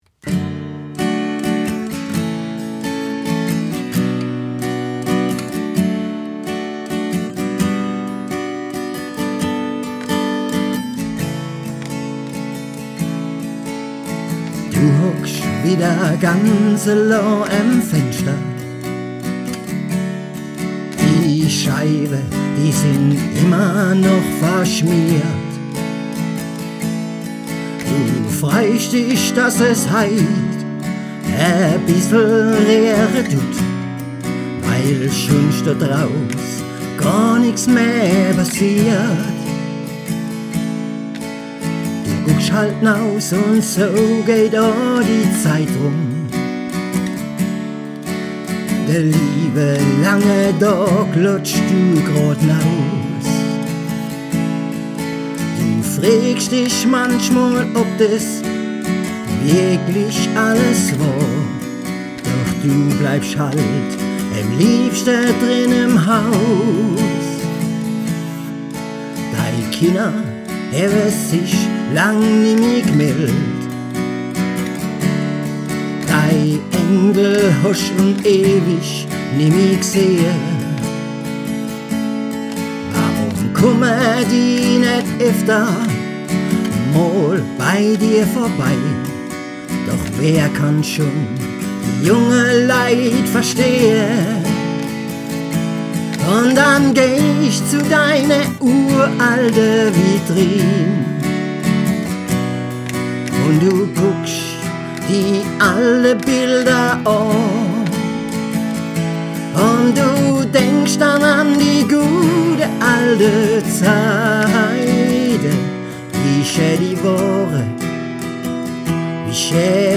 - 2023, Kategorie: Lied, 1.